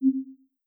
Modern UI SFX / AlertsAndNotifications
GenericNotification5.wav